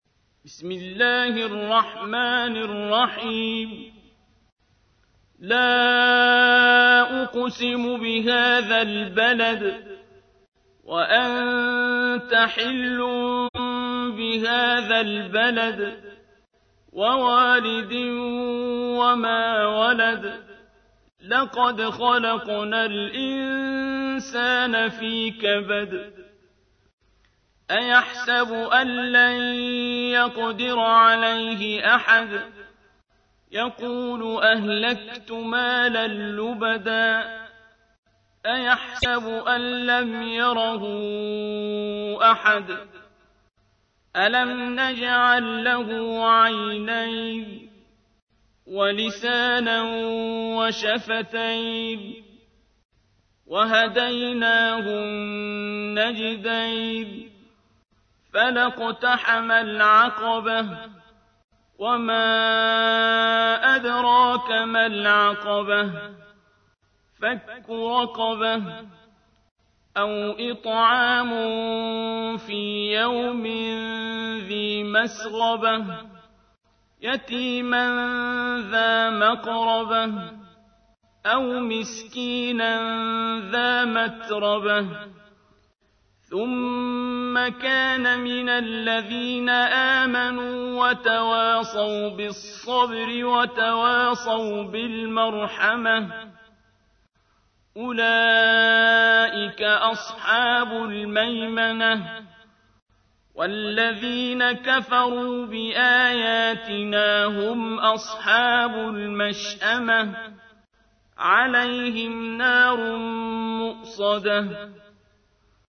تحميل : 90. سورة البلد / القارئ عبد الباسط عبد الصمد / القرآن الكريم / موقع يا حسين